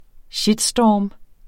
Udtale [ ˈɕidˌsdɒˀm ]